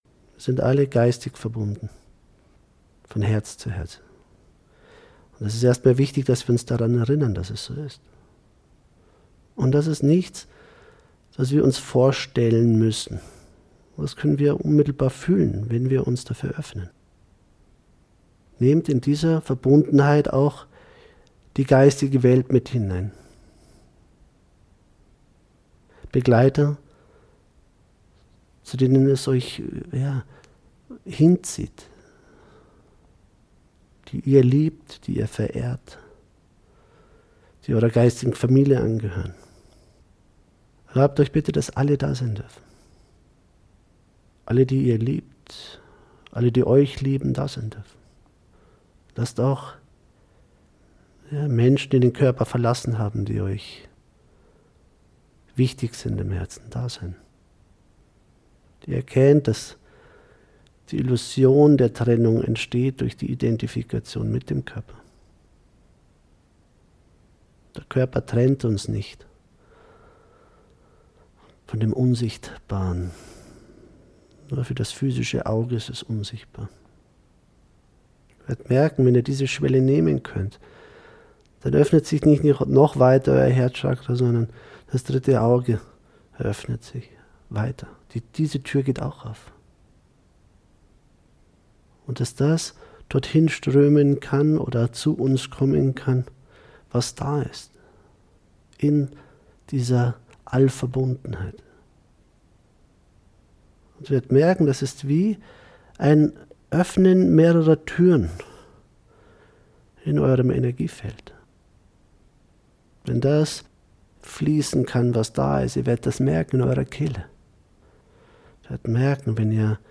Meditation Live-Aufnahme